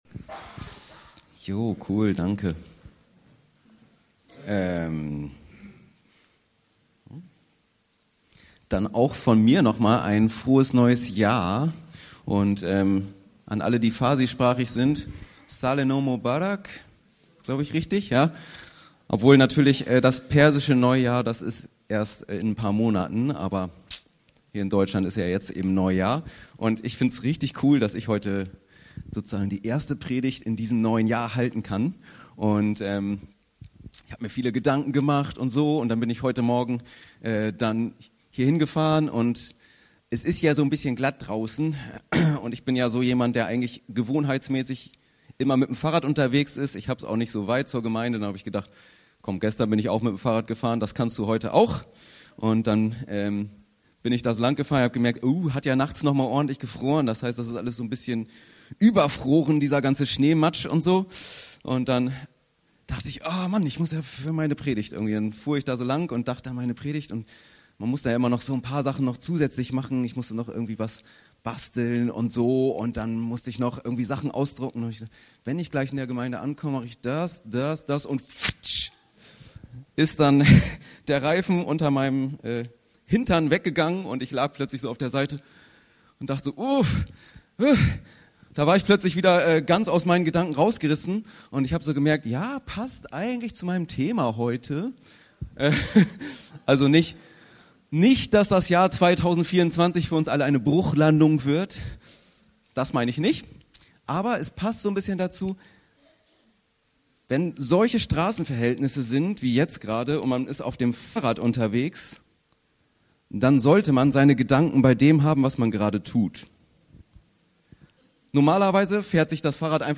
Anstatt sich eine Liste von Vorsätzen zu machen, soll diese Predigt dazu ermutigen nach einem einzigen Wort zu suchen, das dein Jahr bestimmen soll. Die weisesten Persönlichkeiten der Bibel können uns dabei als Vorbild dienen!